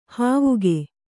♪ hāvuge